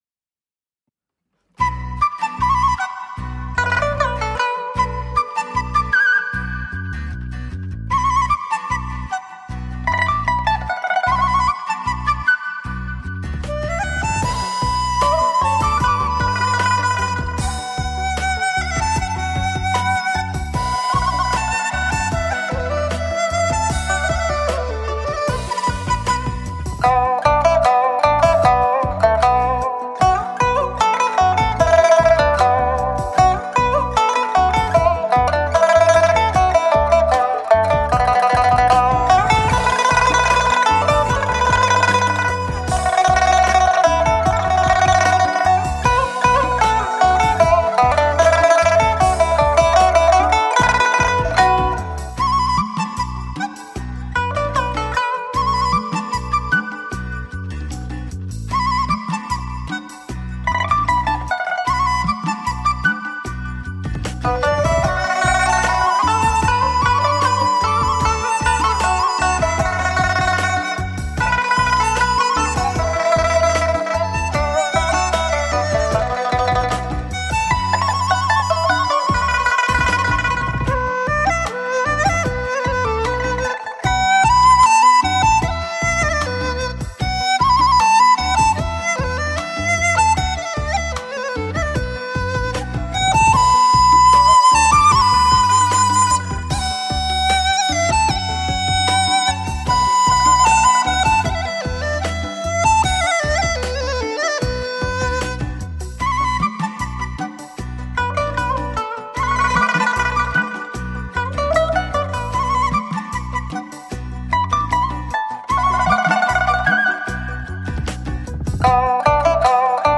笛声传递着情感，旋律热情的舒展，终了，清脆、空灵的笛声，照亮出世界最耀眼的光芒……